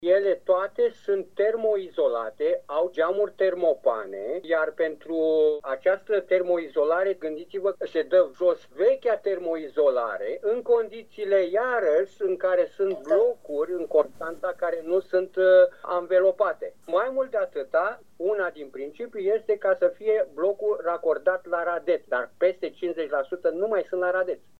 Activistul civic